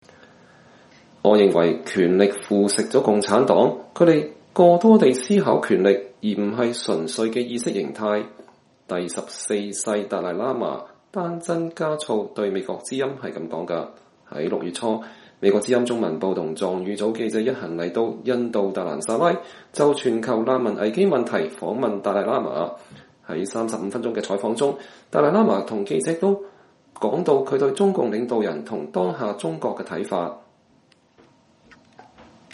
美國之音在達蘭薩拉專訪達賴喇嘛。(2019年6月11日)